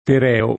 vai all'elenco alfabetico delle voci ingrandisci il carattere 100% rimpicciolisci il carattere stampa invia tramite posta elettronica codividi su Facebook Tereo [ t $ reo ] pers. m. mit. — alla greca ter $ o : con l’acc. scr., Terèo , nel Parini